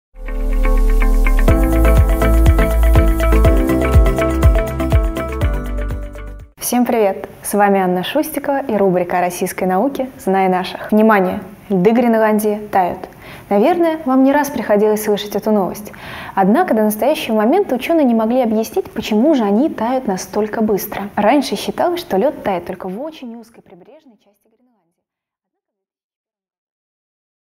Аудиокнига О льдах Гренландии микробах и коррупции | Библиотека аудиокниг